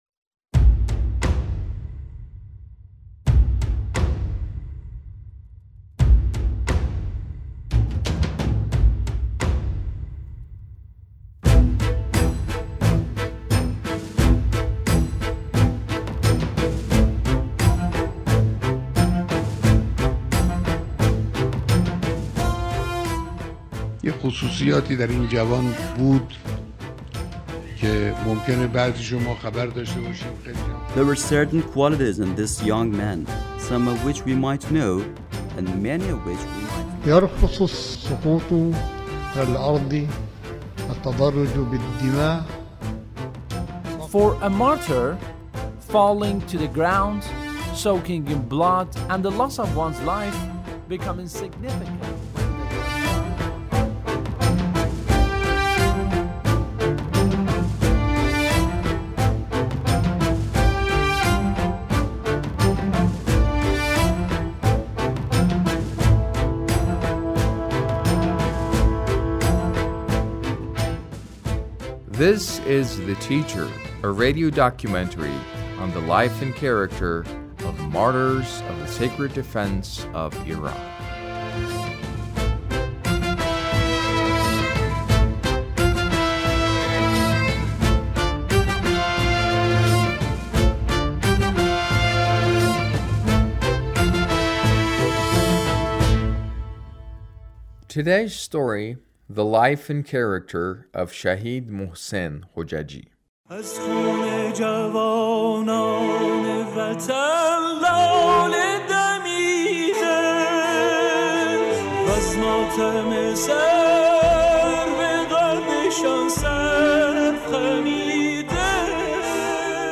A radio documentary on the life of Shahid Mohsen Hojjaji - Part 2